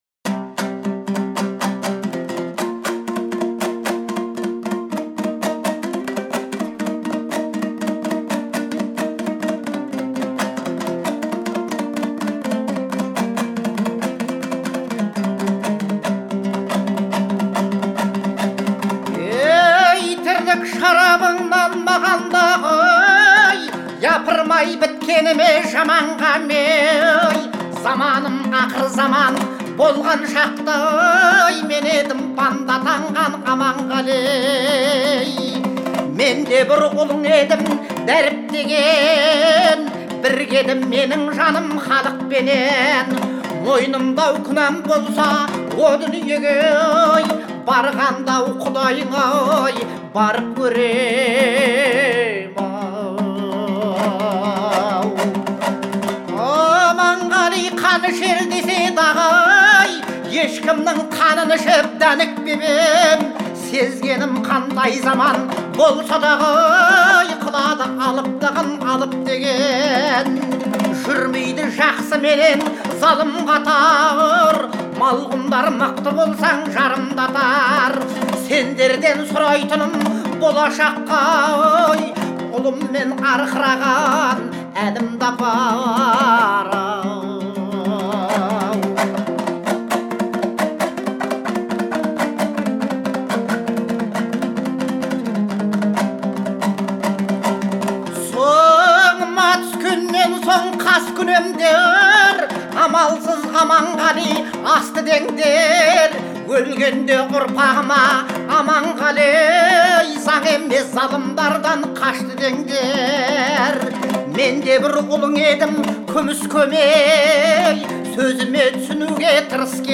это произведение в жанре казахской народной музыки